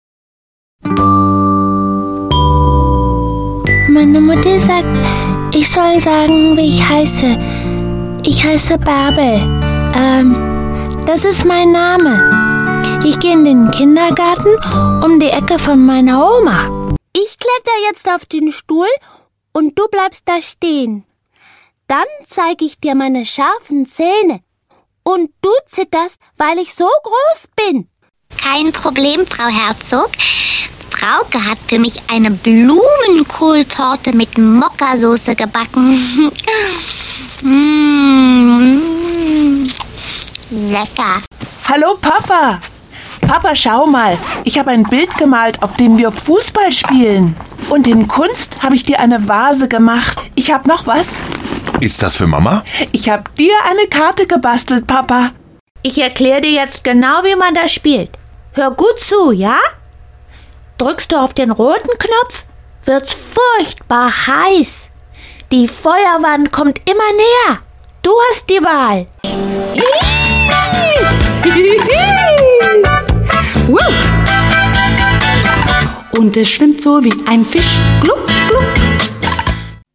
German. Actress, experienced, versatile, kids & adults.
German Corporate and Narration Reel